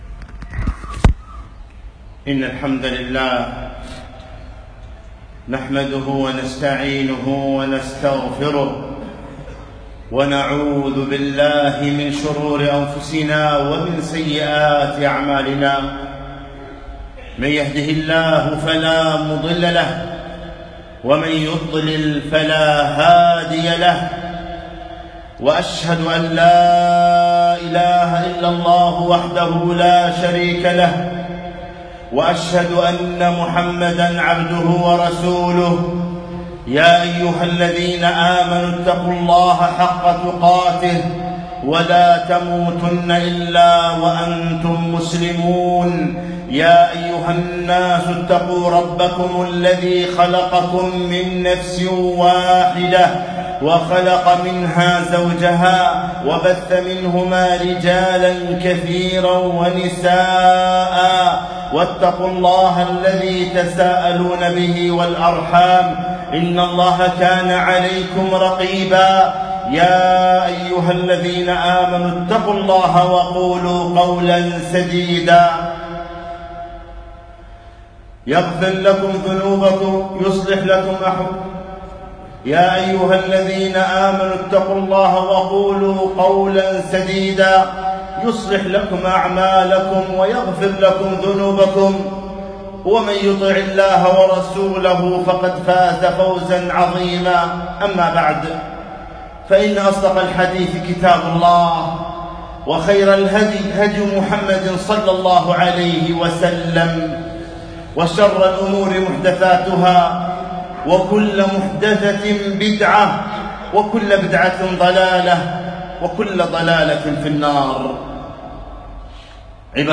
خطبة - تعلق الأخيار بالغفور الغفار